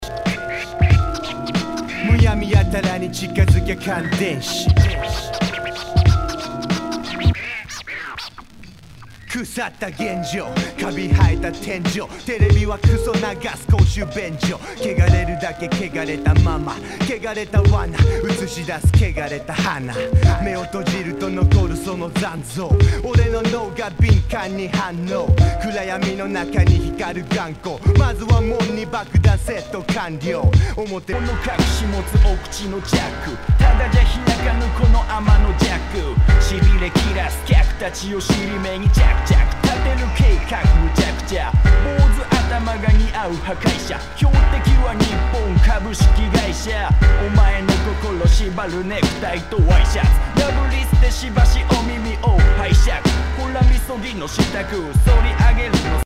HIPHOP/R&B
盤に傷多数あり全体に大きくチリノイズが入ります